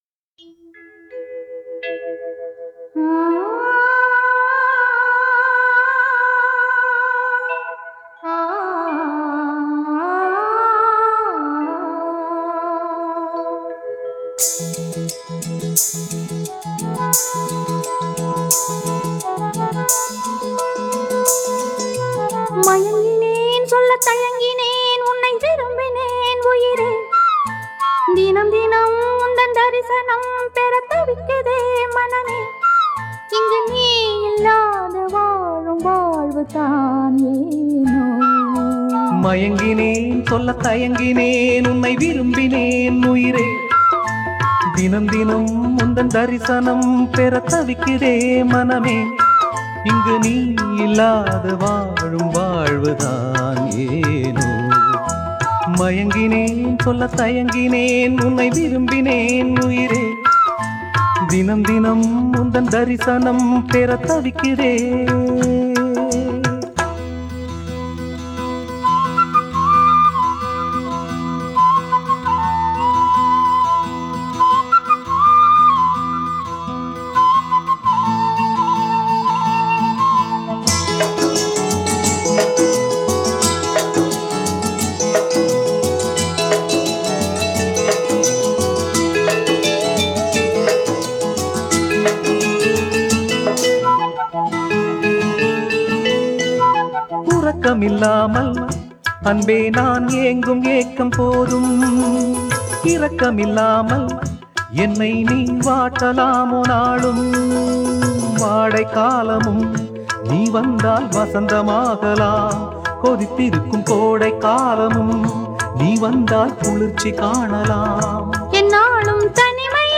the classic song